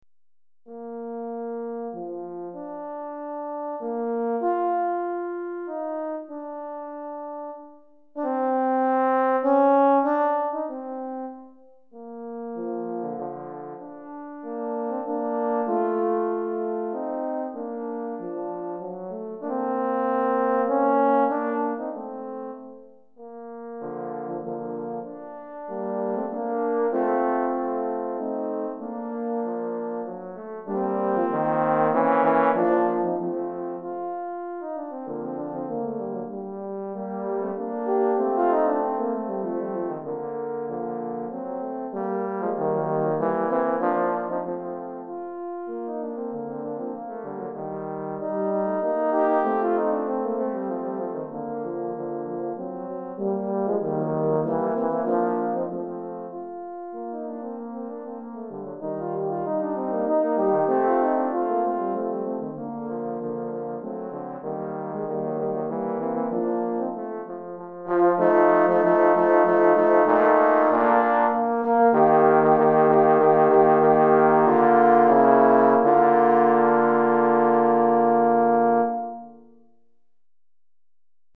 3 Cors